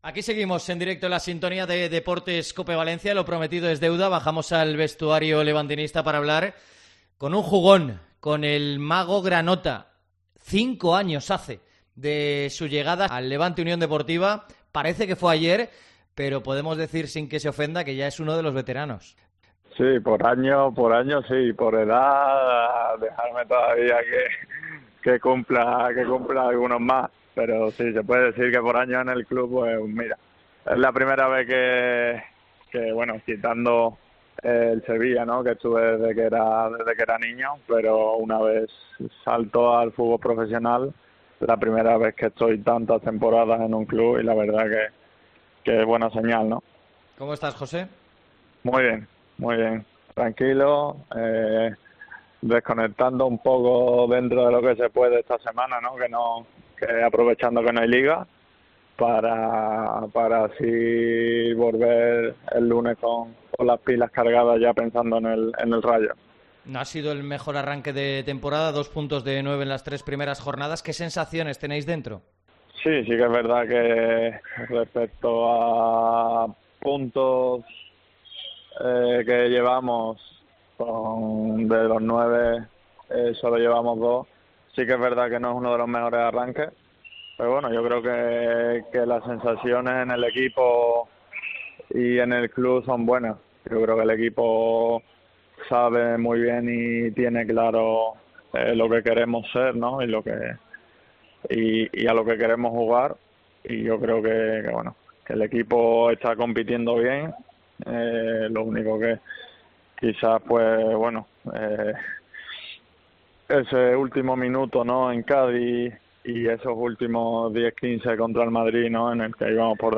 AUDIO. Entrevista a Campaña en COPE